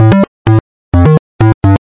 基调舞蹈A2 f2 128 bpm 4
Tag: 最小 狂野 房屋 科技 配音步 贝斯 精神恍惚 舞蹈 俱乐部